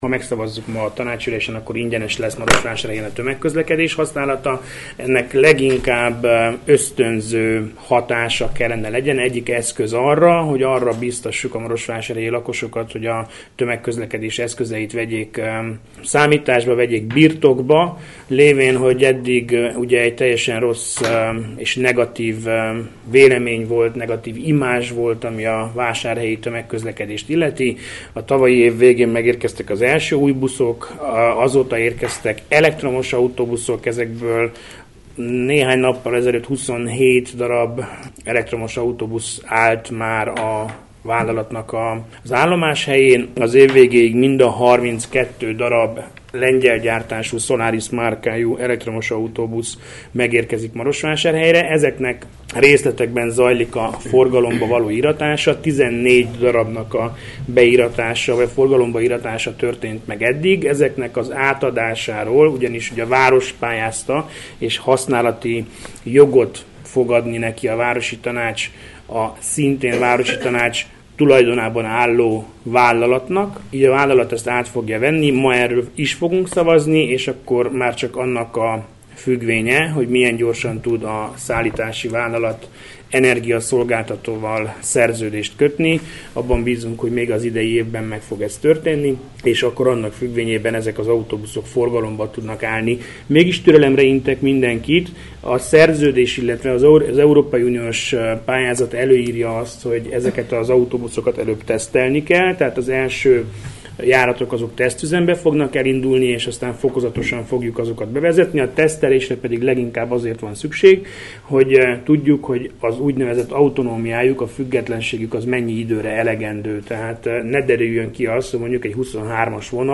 Portik Vilmos alpolgármestert hallják.